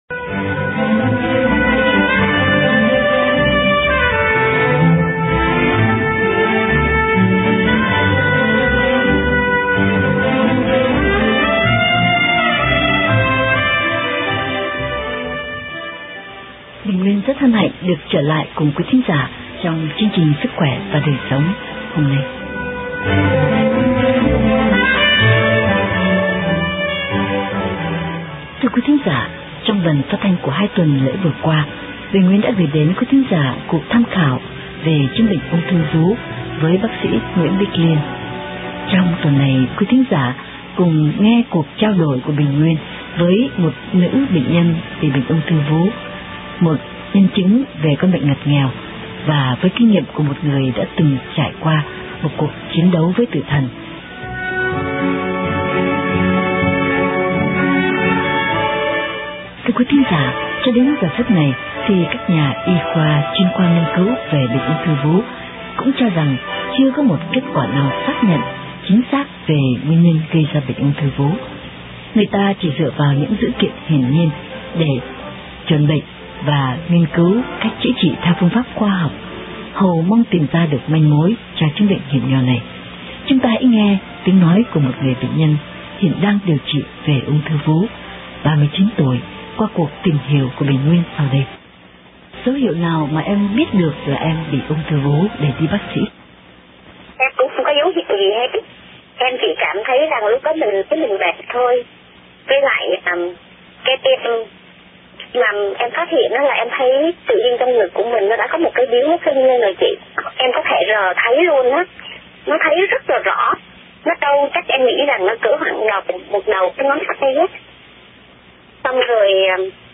một người nữ bệnh nhân bị bệnh Ung thư vú